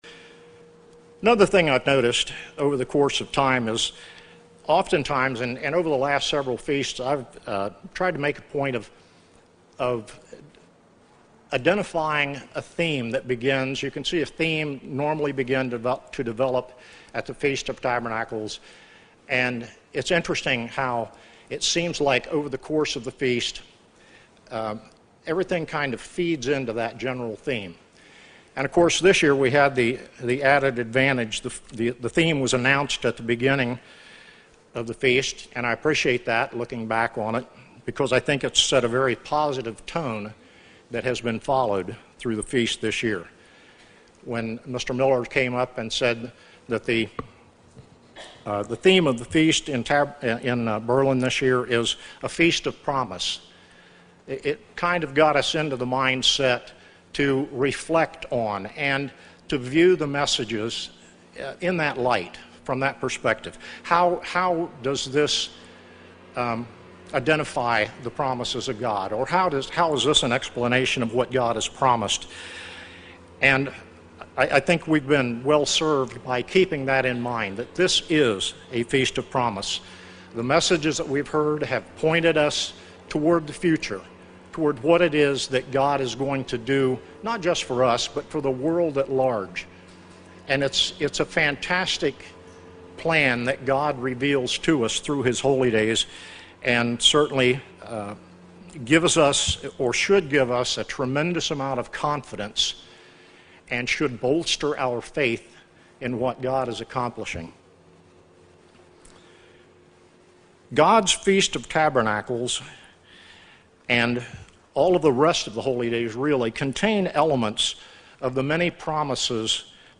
Sermons
Feast of Tabernacles 2020 - Berlin, OH